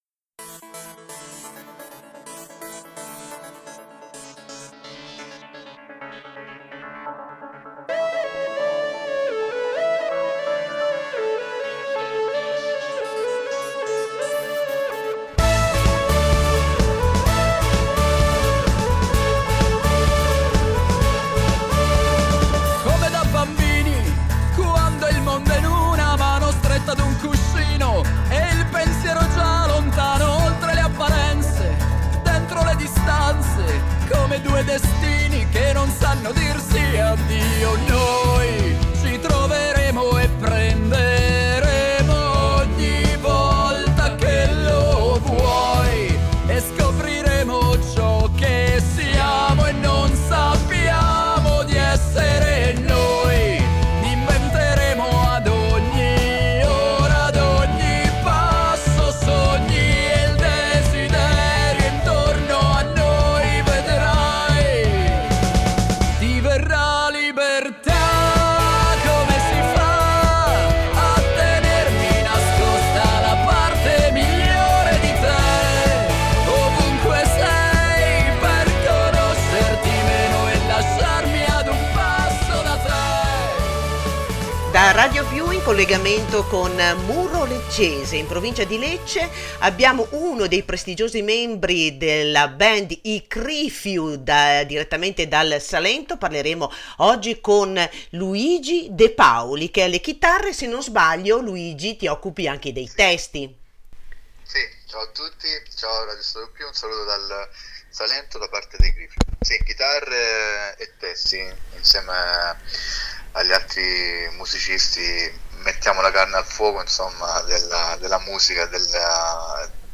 I Crifiu sono un gruppo musicale pop rock salentino attivo dai primi anni 2000. Ai microfoni di Radio Più la band.
intervista-complerta-ai-crifiu-UFFICIALE.mp3